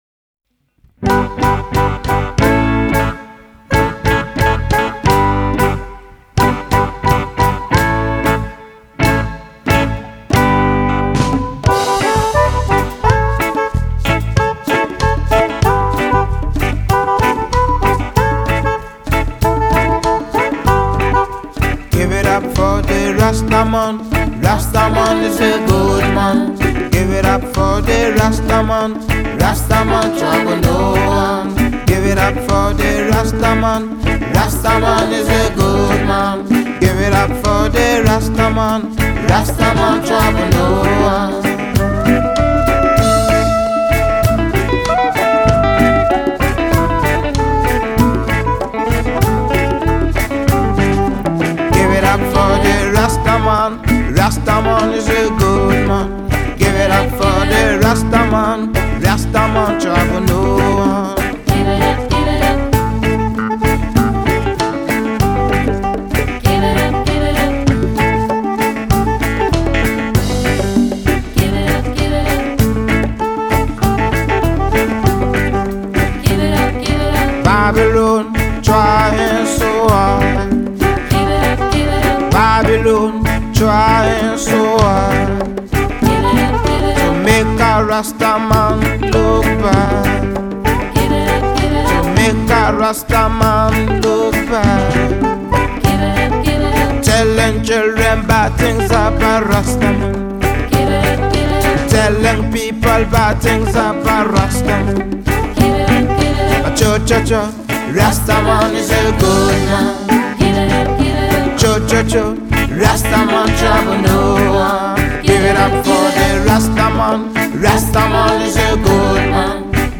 Reggae
Keyboard
Sax and Flute
Backing Vocals
Bass